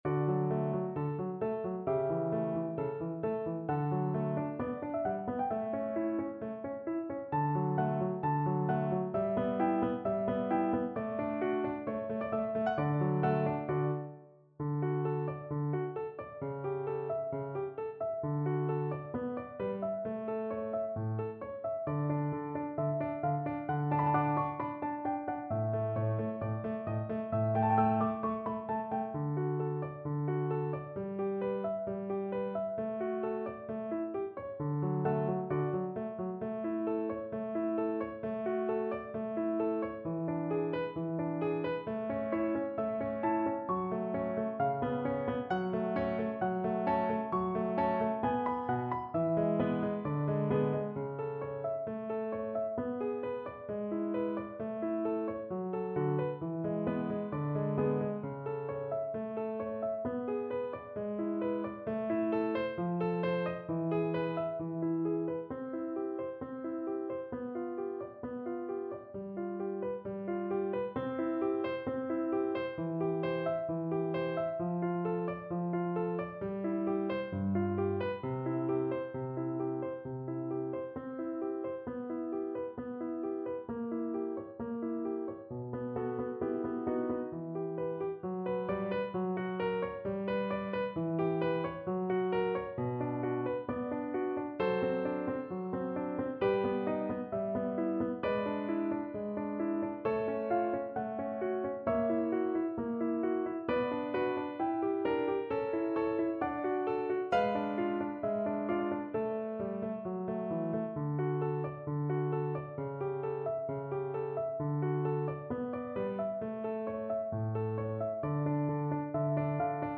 Mozart: Aria Cherubina (na flet i fortepian)
Symulacja akompaniamentu